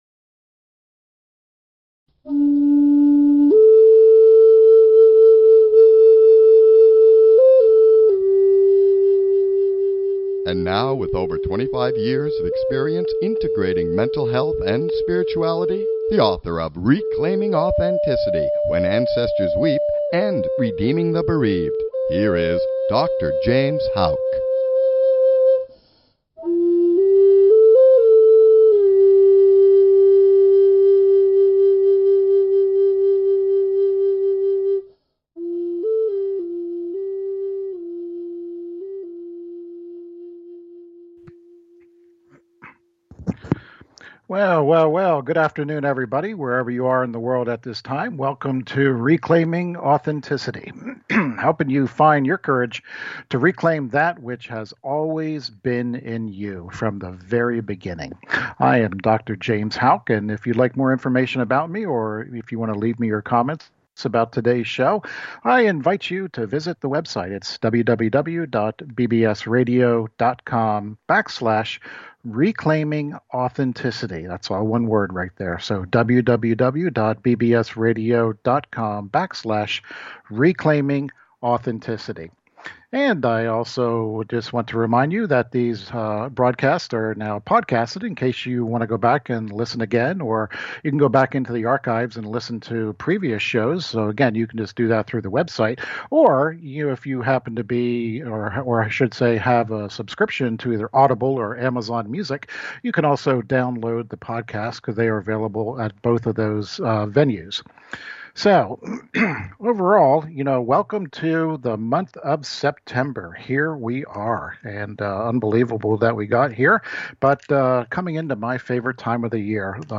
Support my show $2.99/mo or $5.99/mo or $9.99/mo Click HERE SUBSCRIBE TO TALK SHOW A Subscription is NOT REQUIRED to listen to my shows, but it is greatly appreciated!